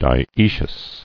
[di·oe·cious]